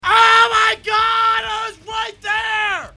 Tags: michael jones rage quit rooster teeth achievement hunter rage gaming angry anger